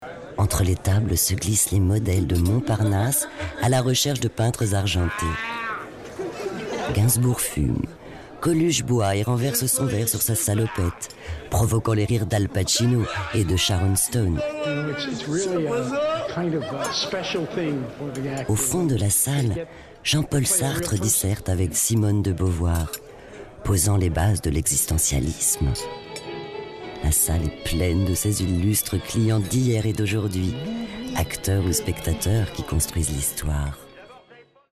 Sprechprobe: Sonstiges (Muttersprache):
Voice over female